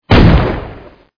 shotgun.mp3